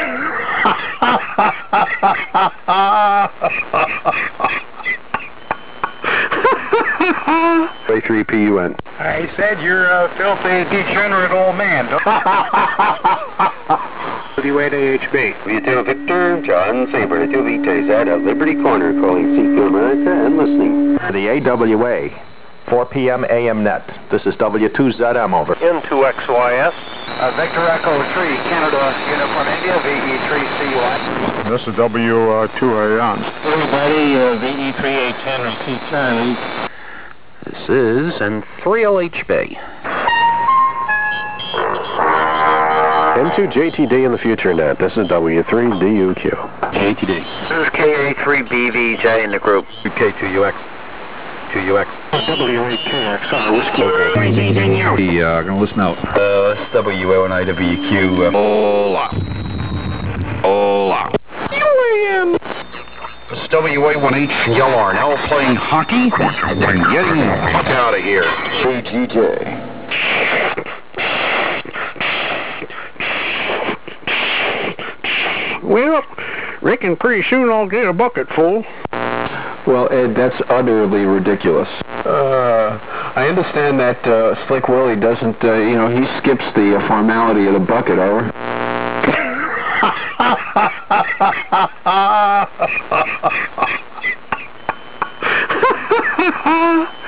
Northeast 75 Meters AM Sounds from Years Past (Wow what memories!)